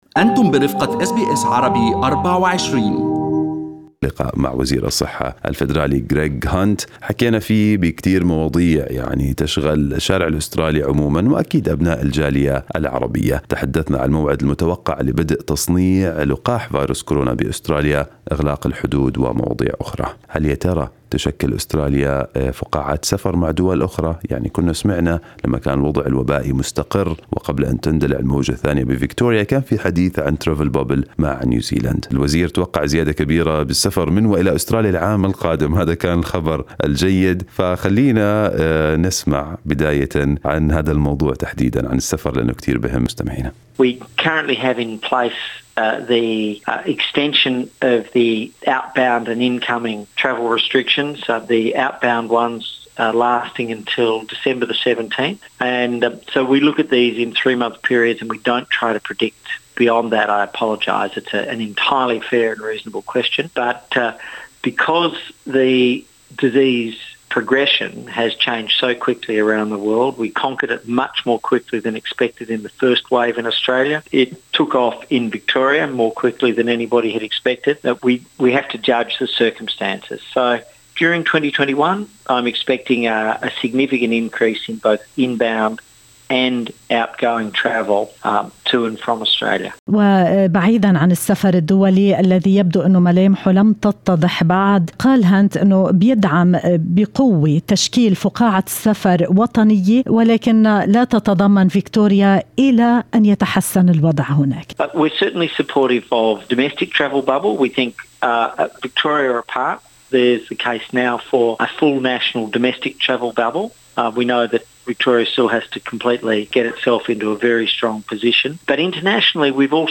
وقال هانت في حديث لأس بي أس عربي24 أن ولاية فكتوريا لا تزال في وضع لا يسمح بشمولها في فقاعة السفر المقترحة، تاركاً الباب مفتوحاً لوضع خطة أكثر وضوحاً مع تحسن الوضع الوبائي هناك.